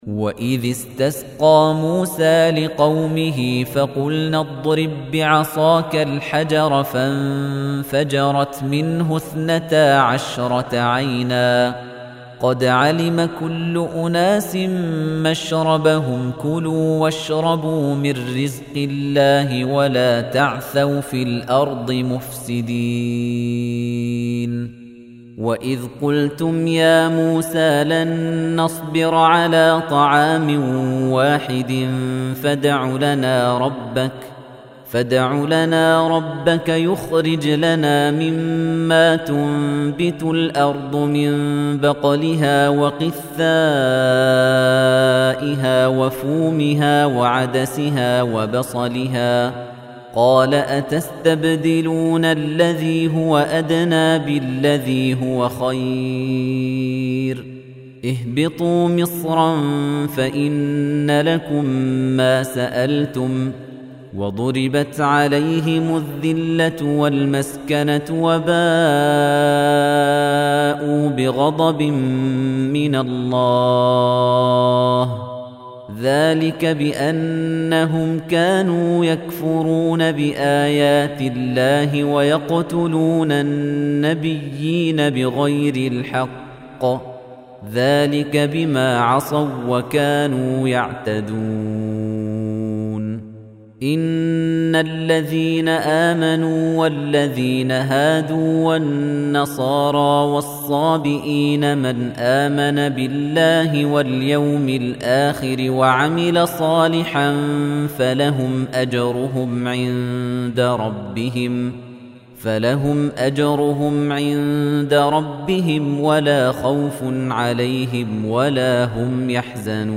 Soothing recitation of the